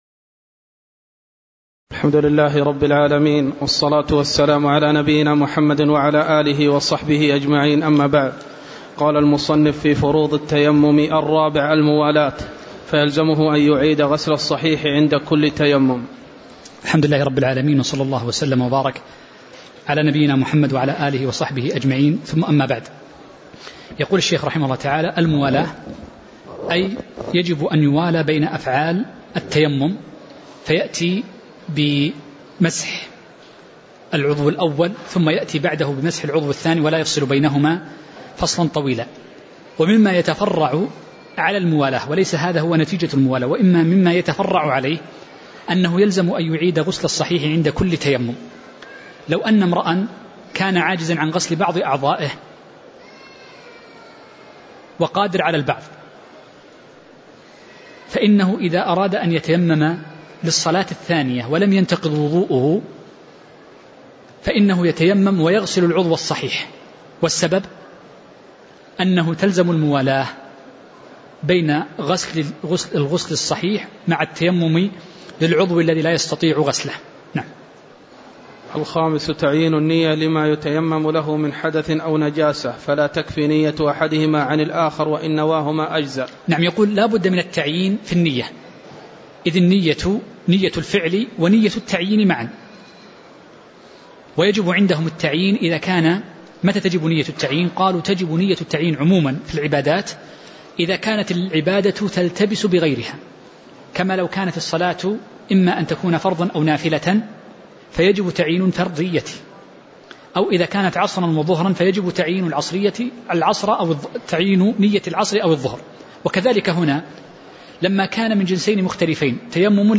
تاريخ النشر ٢ ذو القعدة ١٤٤٠ هـ المكان: المسجد النبوي الشيخ